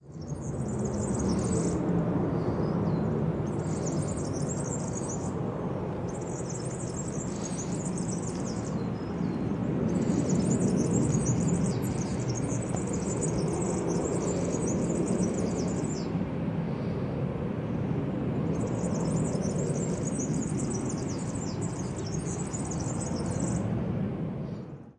描述：在这个音频中，我们可以听到Serin的鸟鸣声。用Zoom H1录音机录制。